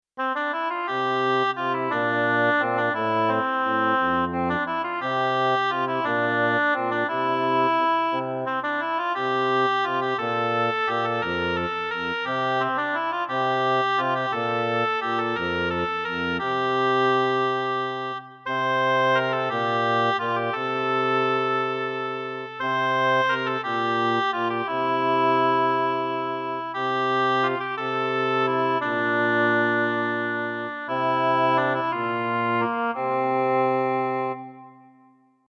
ambiente
melodía
sintonía